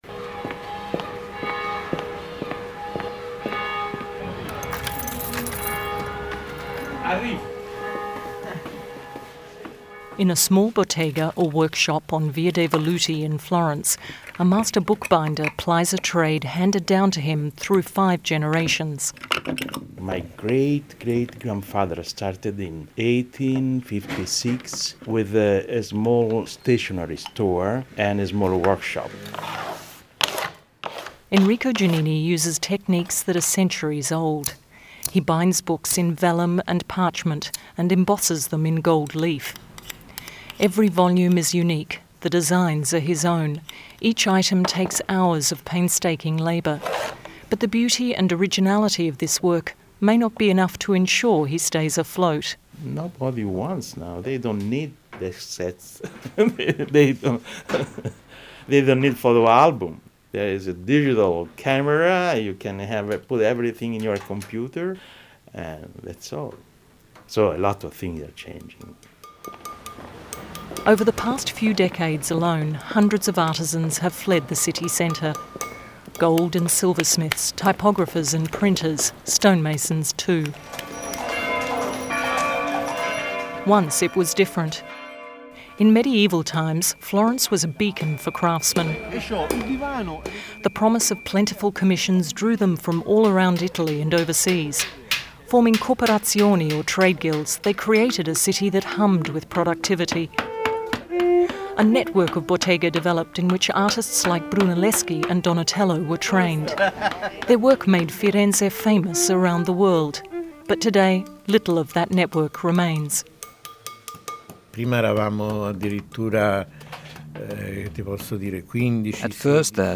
Location: Florence, Italy
Radio feature story